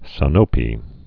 (sə-nōpē)